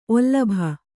♪ ollabha